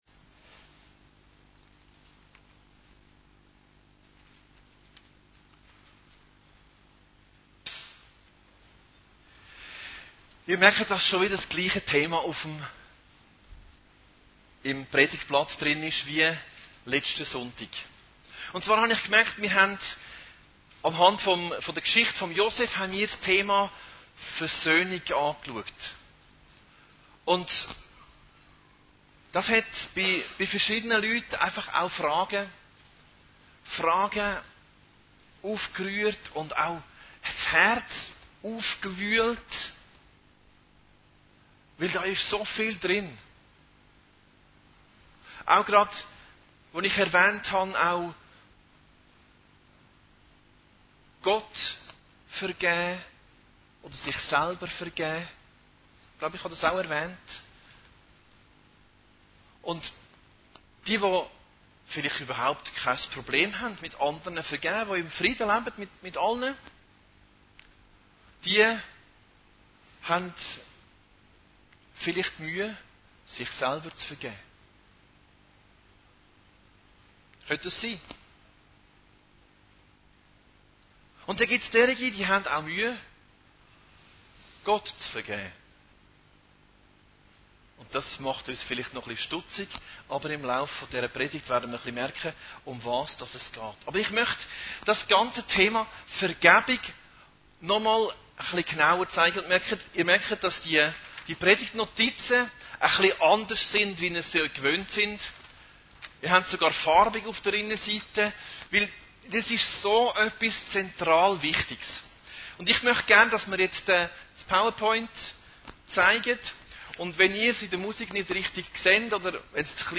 Predigten Heilsarmee Aargau Süd – Vergebung/Versöhnung